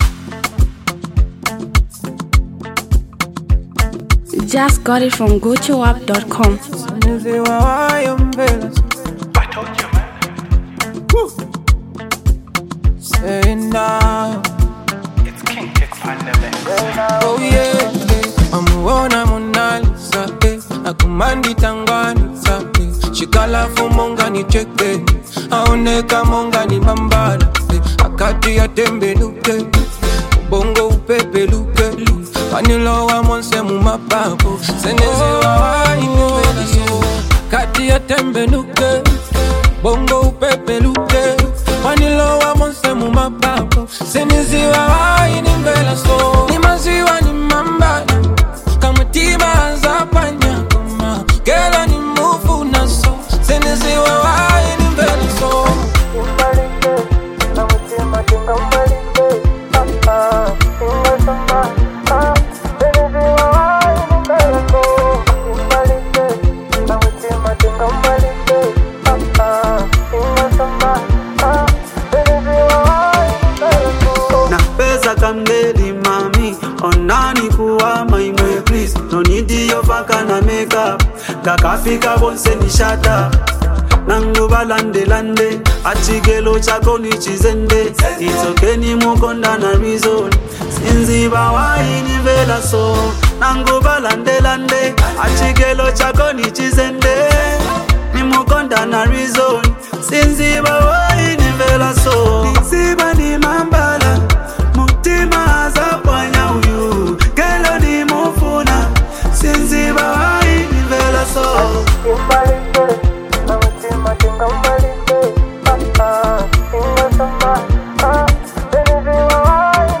powerful melodic sound